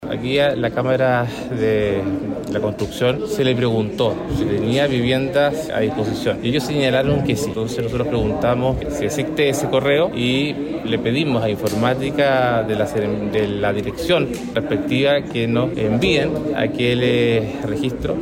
El diputado de Renovación Nacional y presidente de la comisión, Andrés Celis, aseguró que esta situación es inaceptable y pedirán los registros de correos electrónicos para esclarecer realmente qué fue lo que pasó con la oferta de la Cámara Chilena de la Construcción.